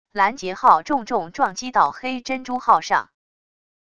拦截号重重撞击到黑珍珠号上wav音频